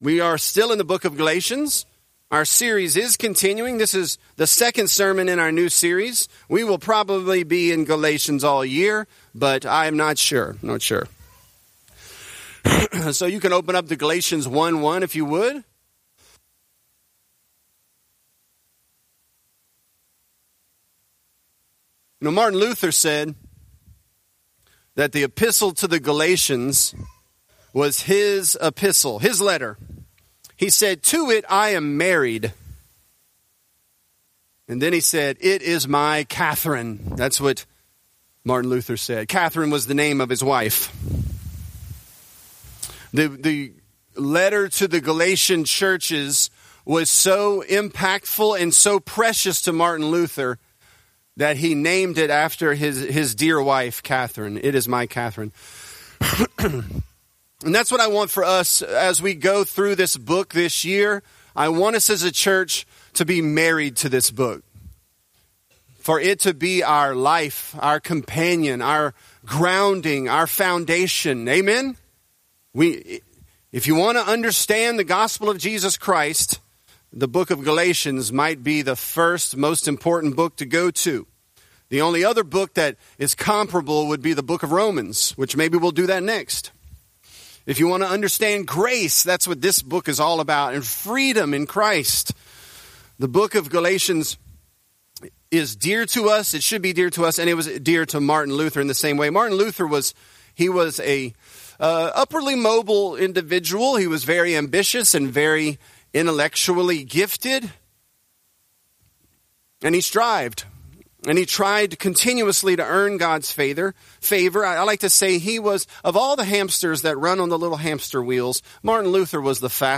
Galatians: Grace to You | Lafayette - Sermon (Galatians 1)